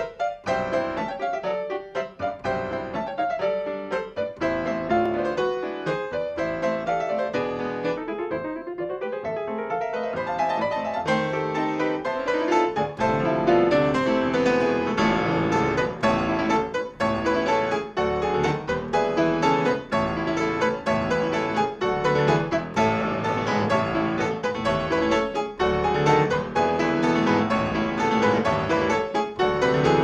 Solo Instrumentals